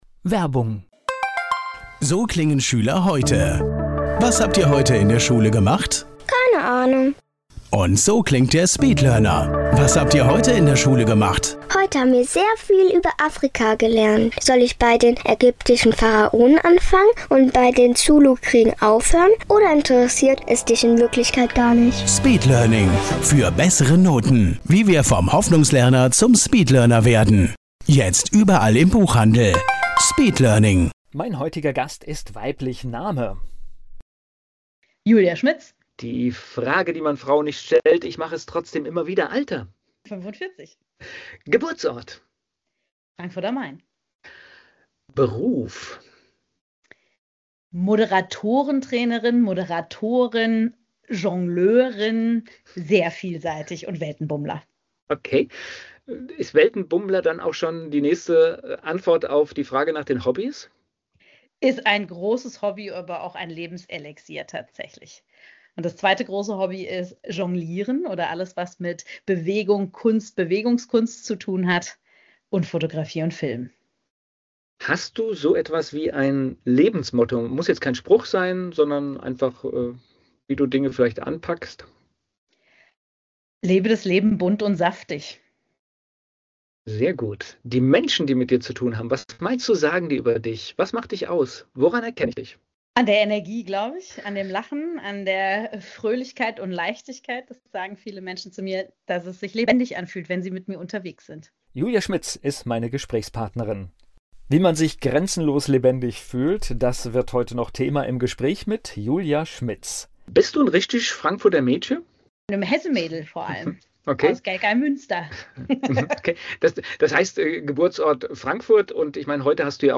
Ein Gespräch über Freiheit, Energie, Neugier – und darüber, warum man nicht warten sollte, bis „irgendwann“ beginnt.